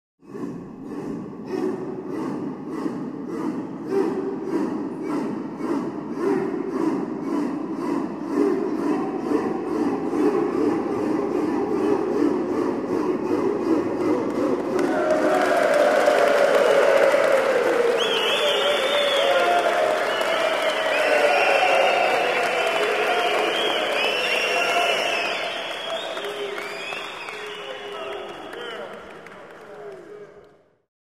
Звуки поддержки команды, громкие крики и аплодисменты